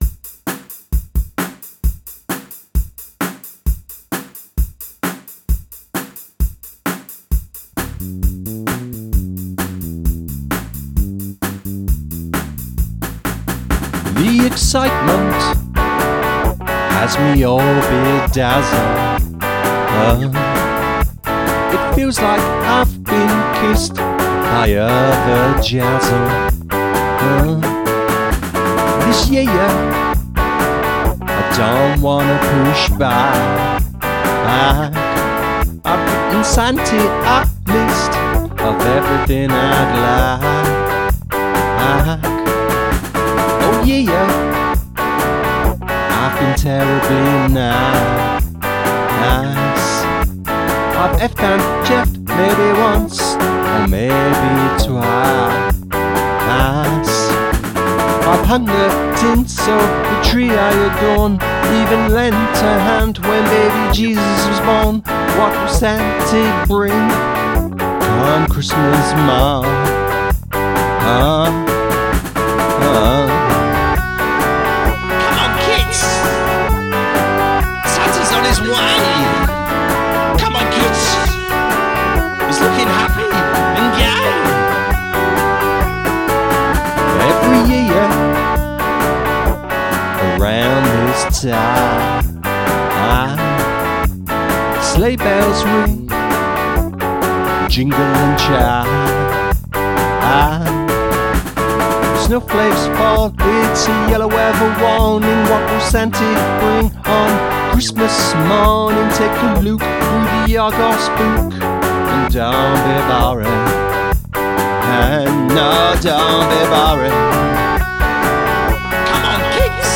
Christmas ditty